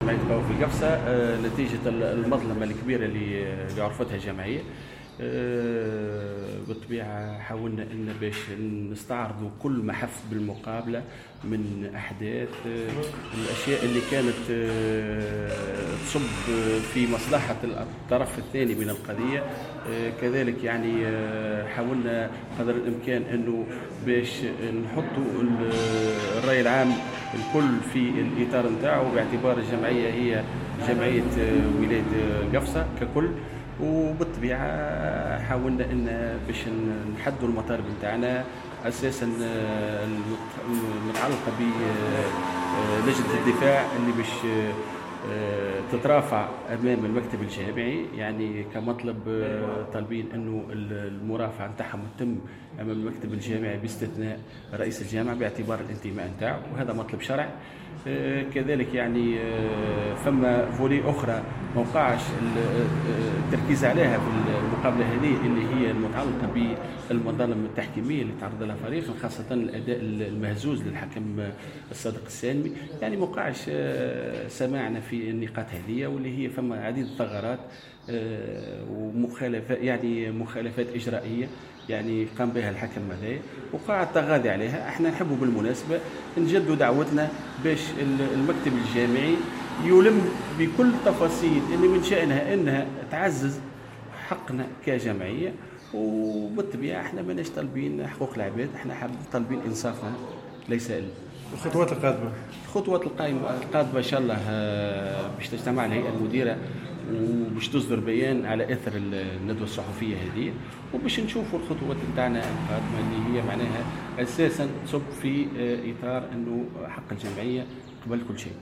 عقدت الهيئة المديرة لقوافل قفصة اليوم الجمعة غرة جوان 2018 ندوة صحفية تطرقت خلالها...